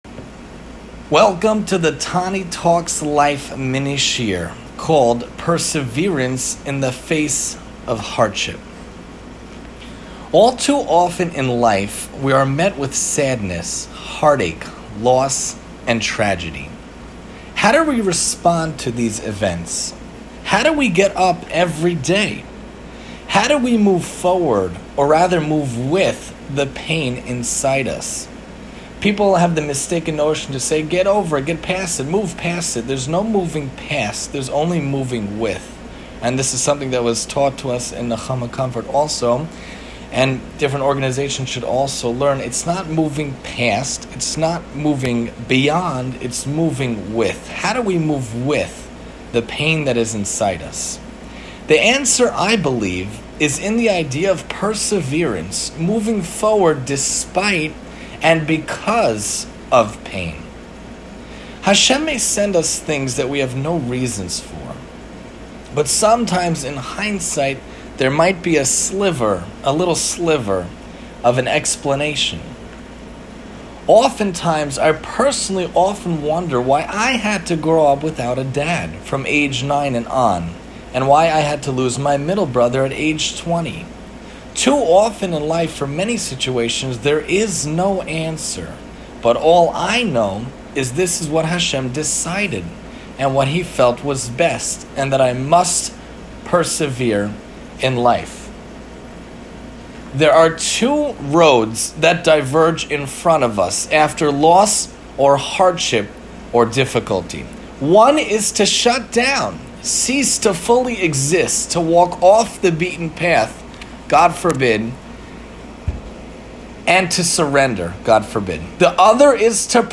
Mini-Lecture: Perseverance In The Face Of Adversity